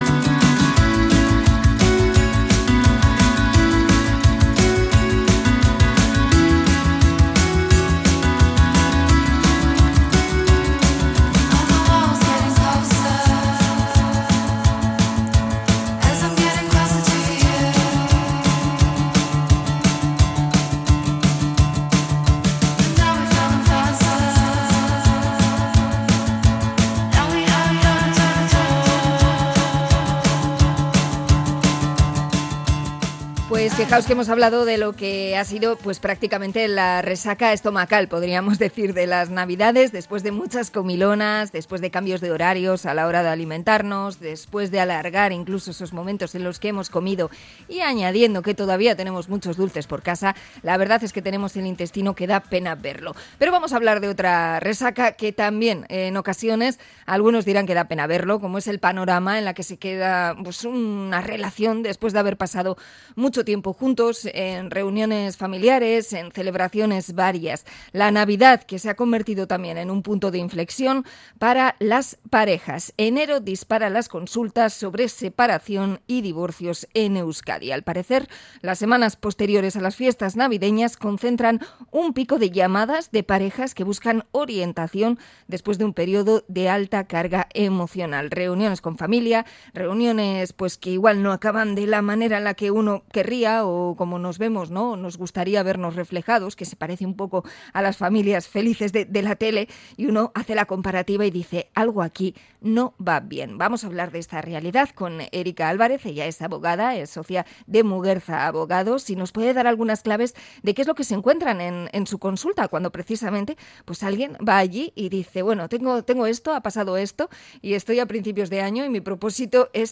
Entrevista a abogada sobre los divorcios tras navidades